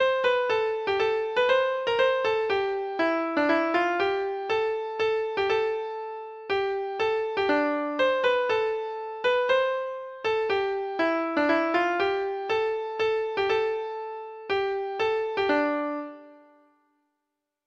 Treble Clef Instrument version
Folk Songs